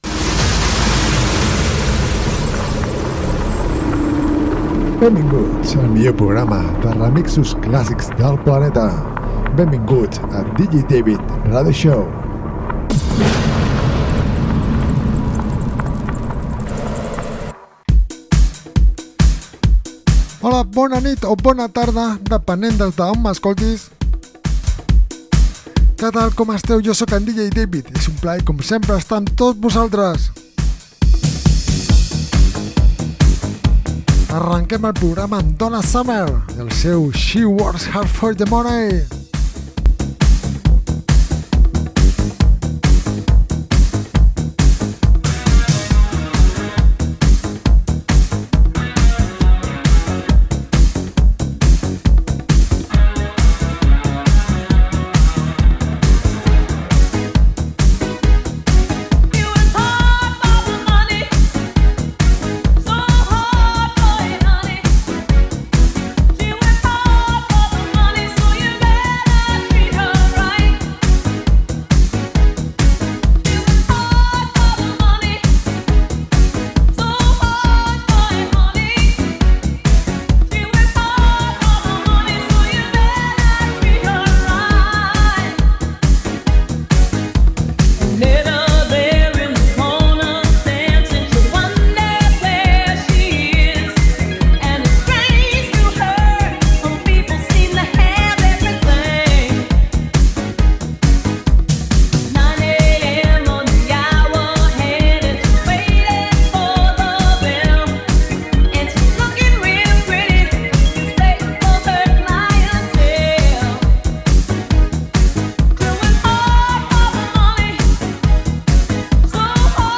programa de ràdio